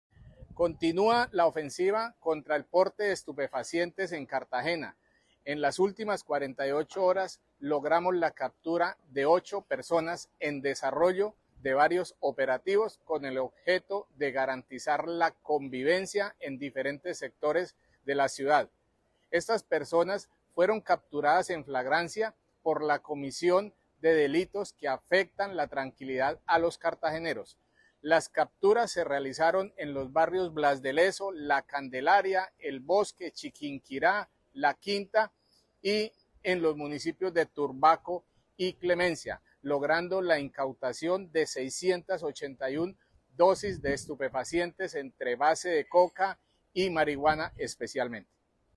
Coronel Wharlinton Iván Gualdrón Gualdrón, Comandante Policía Metropolitana de Cartagena, captura de ocho jibaros